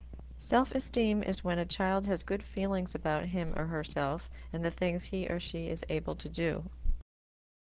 When you see the speaker speaker after each of the definitions you can hear the pronunciation of the term and what it means.